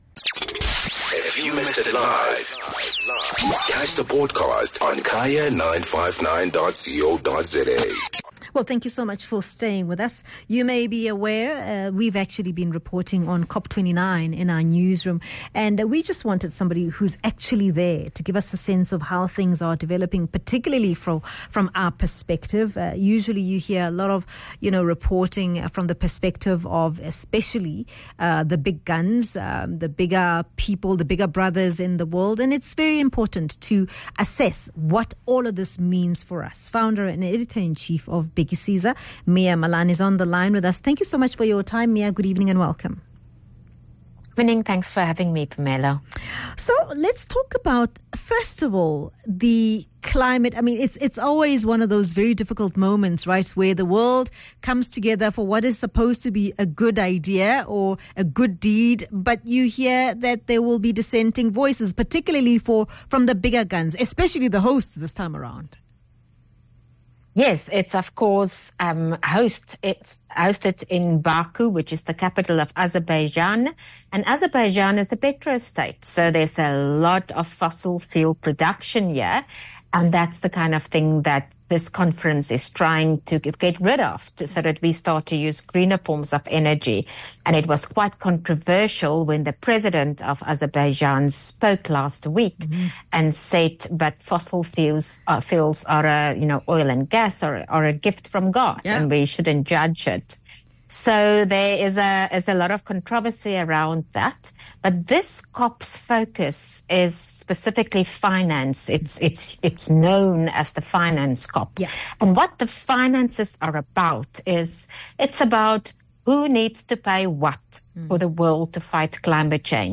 18 Nov .Health Feature: Climate Change